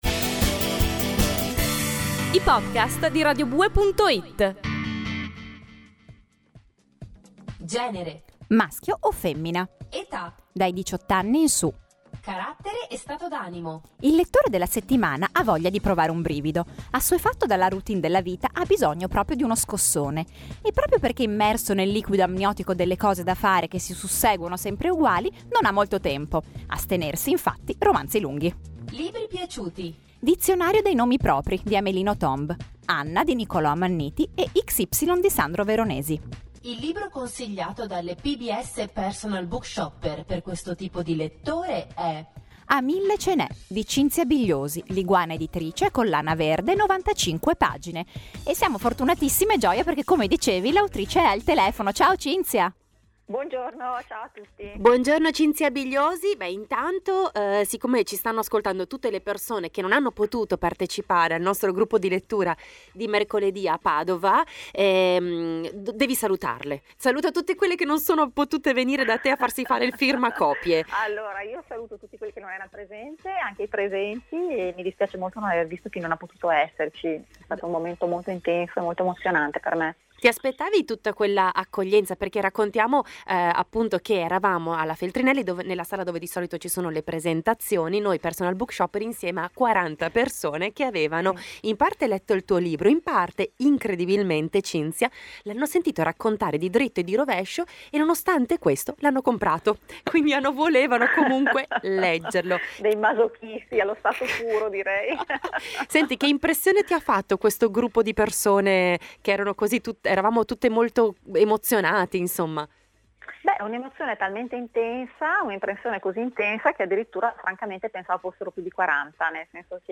Ascolta premendo play (o scarica il podcast qui) le impressioni dell’autrice sull’accoglienza che ha ricevuto al club di lettura: